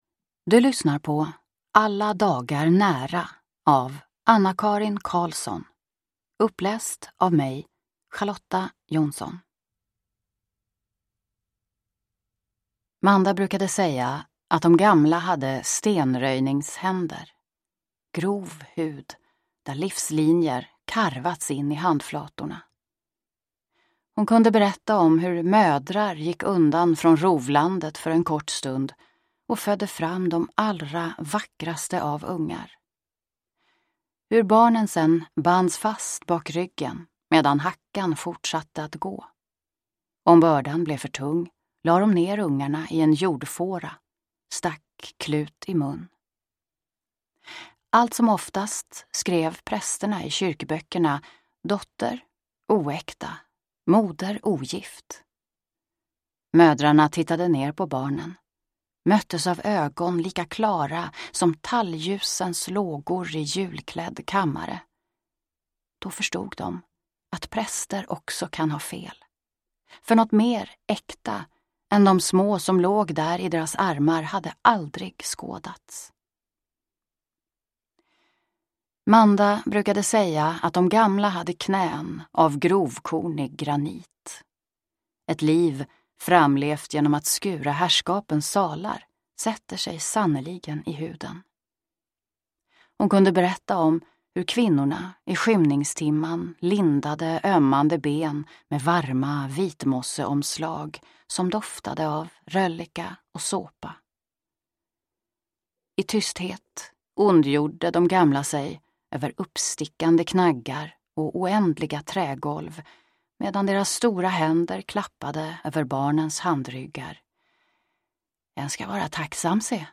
Alla dagar nära – Ljudbok